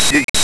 A Go application to generate numbers station like audio output